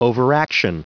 Prononciation du mot overaction en anglais (fichier audio)
Prononciation du mot : overaction